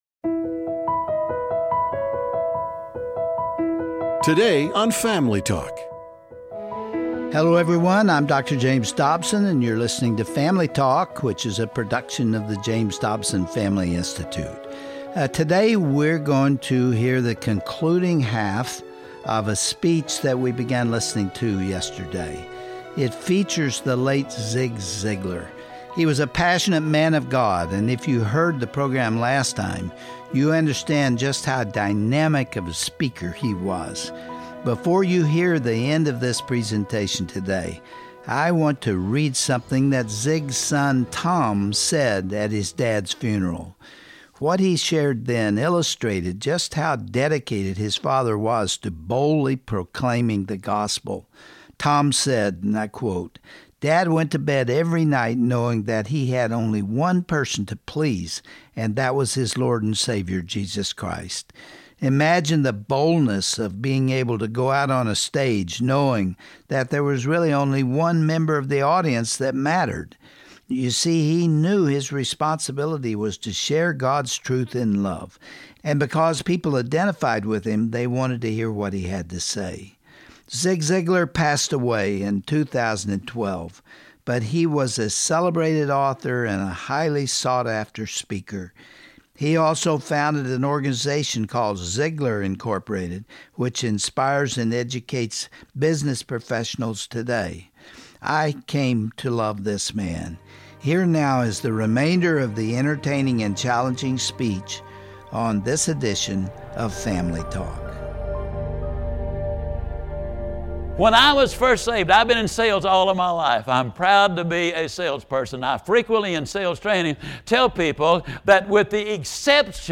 Every day we must honor God by living out our faith, and boldly sharing the Gospel with anyone who will listen. you will hear the remainder of a powerful presentation from the late speaker Zig Ziglar. He explains what it means to be a contagious Christian, and how our faith should influence every other aspect of life.